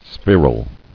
[spher·al]